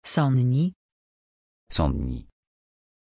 Pronunciation of Sonni
pronunciation-sonni-pl.mp3